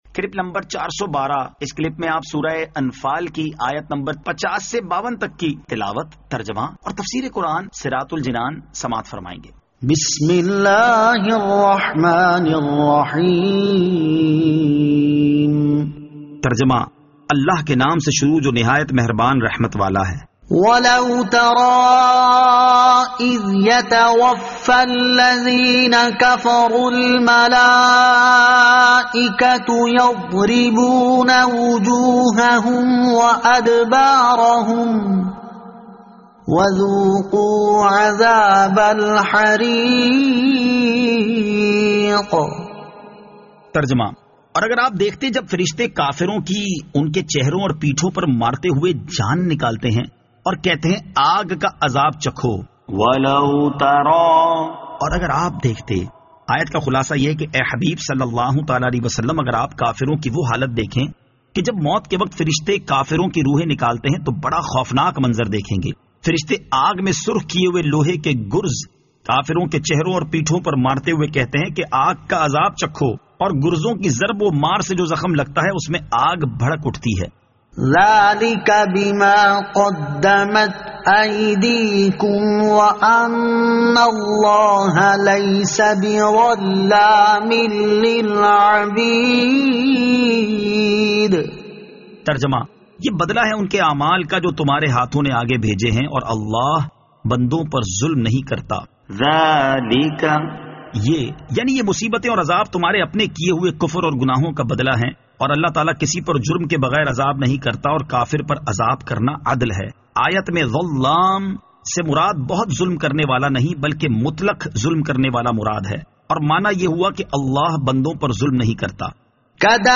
Surah Al-Anfal Ayat 50 To 52 Tilawat , Tarjama , Tafseer